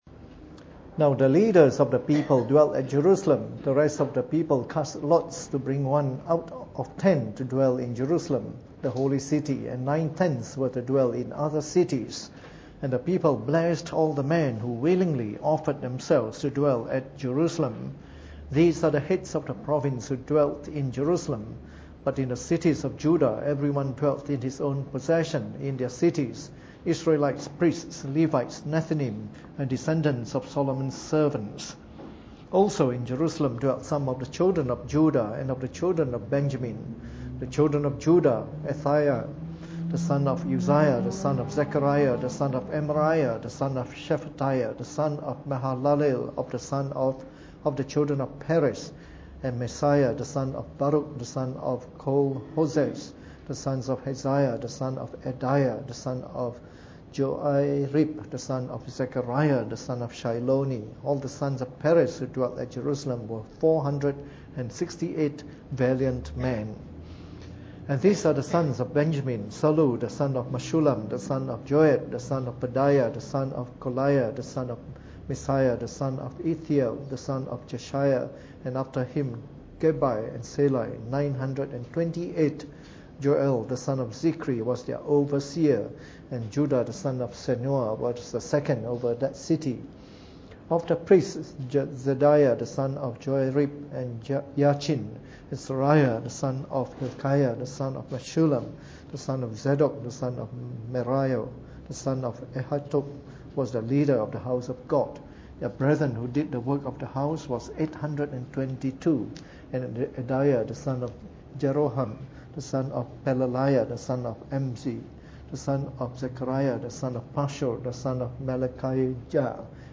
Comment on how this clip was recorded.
Preached on the 16th of July 2014 during the Bible Study, from our series of talks on the Book of Nehemiah.